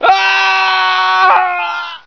scream24.ogg